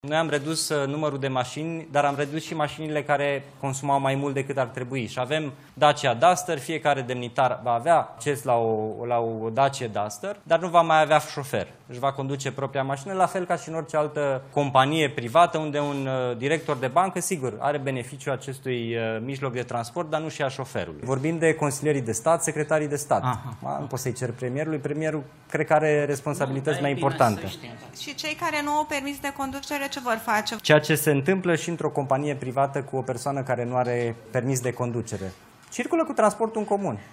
” Am redus numărul de mașini, dar am redus și mașinile care consumau mai mult decât ar trebui. Și avem Dacia Duster, fiecare demnitar va avea acces la o Dacia Duster, dar nu va mai avea șofer. Își va conduce propria mașină, la fel ca în orice altă companie” a explicat Mihai Jurca într-o conferință de presă.